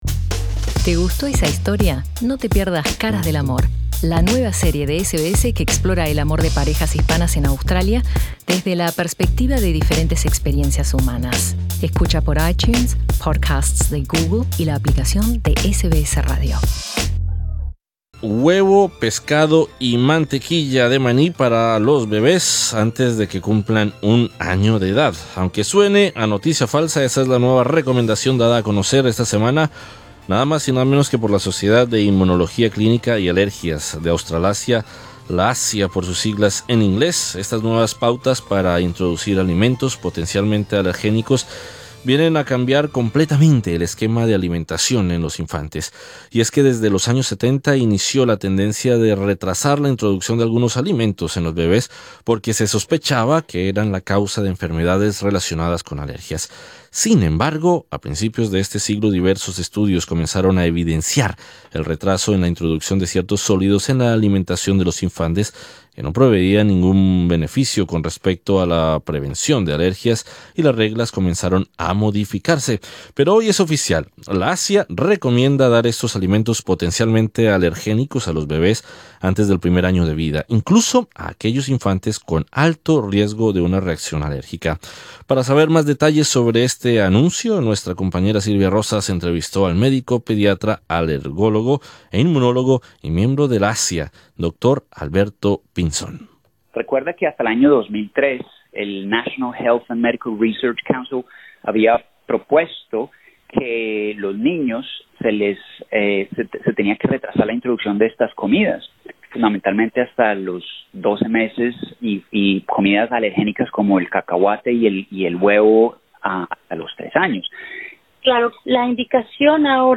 Entrevista con el médico pediatra